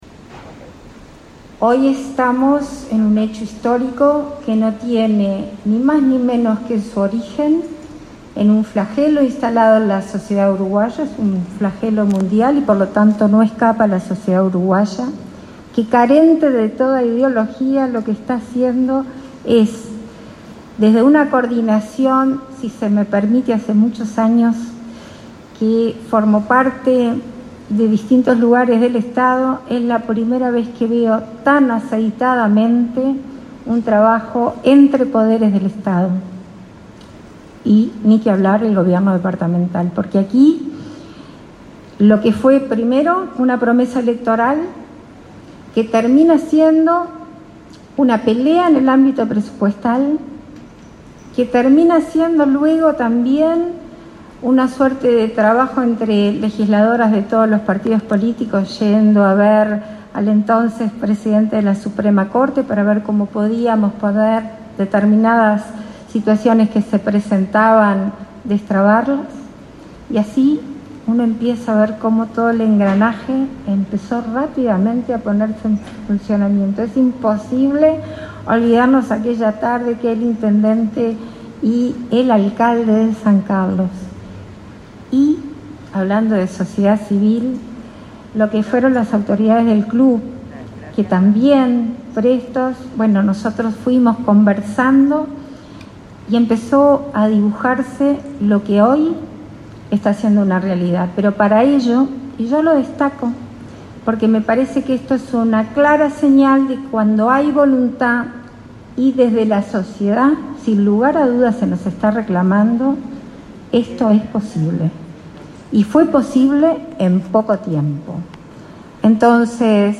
Palabras de la presidenta en ejercicio, Beatriz Argimón
La presidenta en ejercicio, Beatriz Argimón, participó este martes 22, en la inauguración de dos juzgados letrados especializados en violencia basada